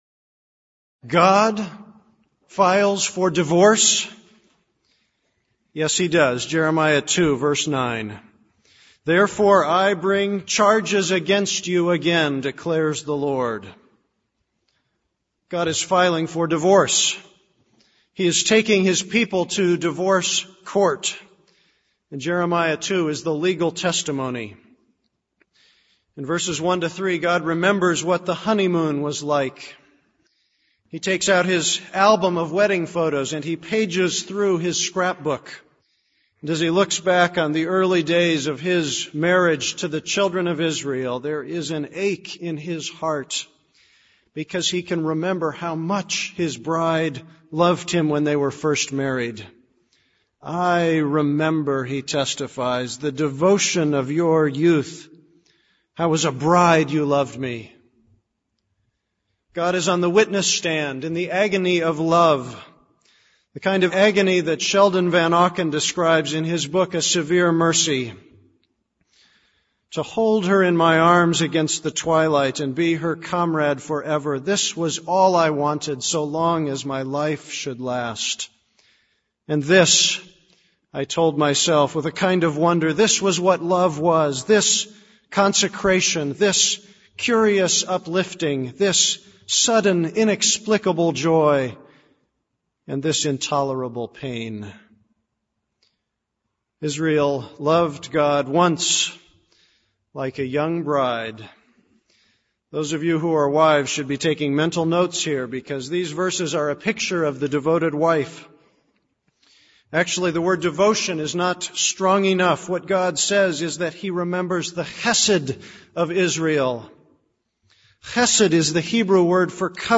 This is a sermon on Jeremiah 2:1-37.